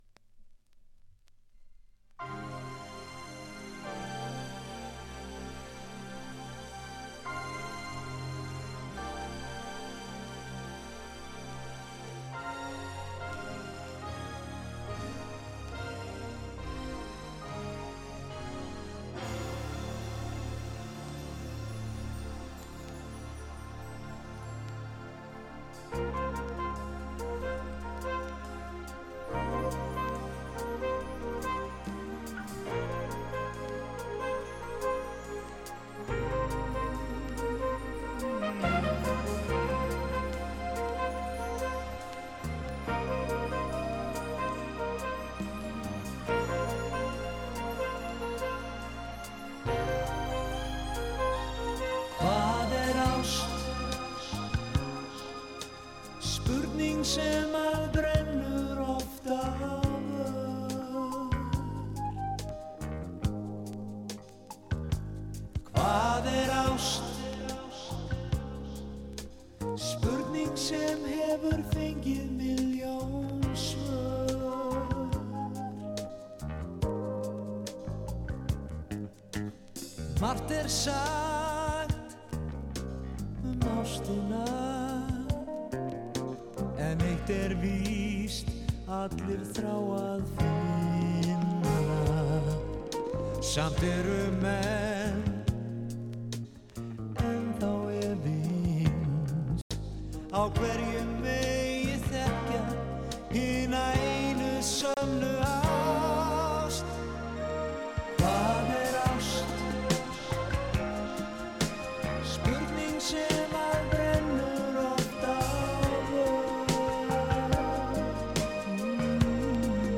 北欧アイスランディック・メロウ
転調マジックなソフトロック調
シャレたサビを持つ
スペーシーなメロウ・ディスコ